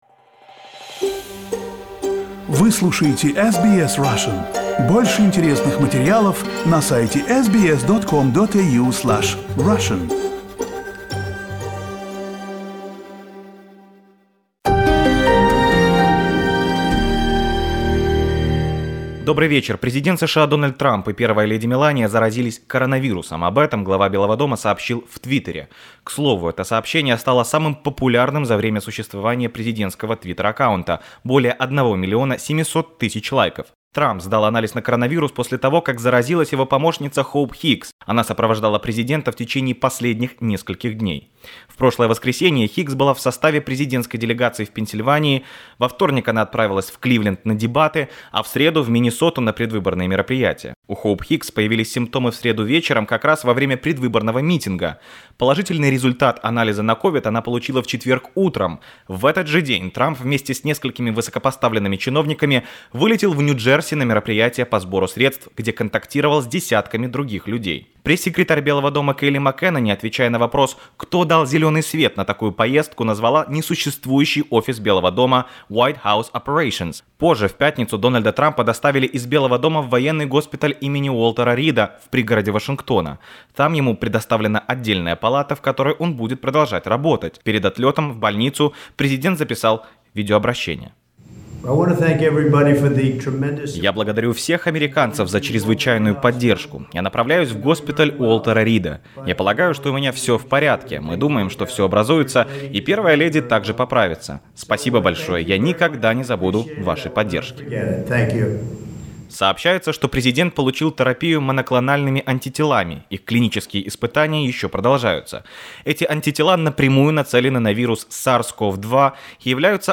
Больше информации - в аудио-репортаже.